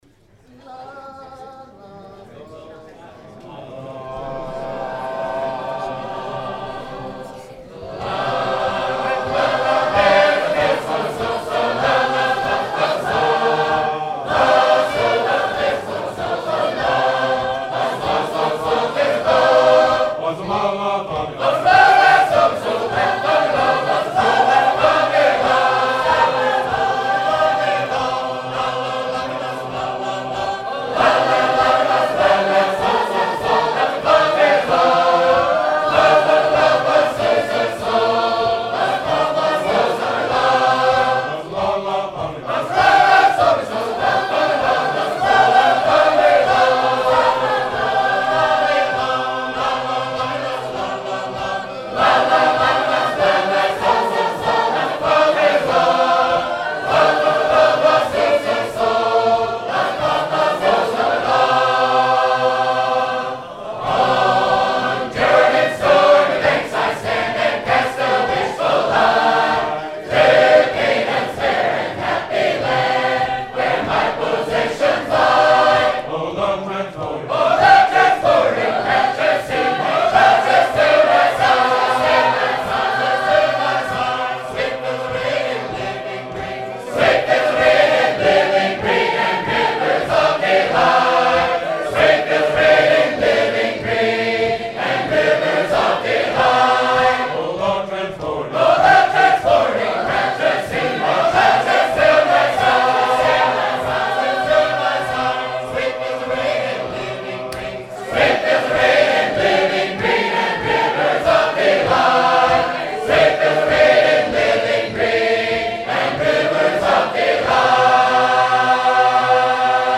Henagar Union – July 4-5, 2009 | Southern Field Recordings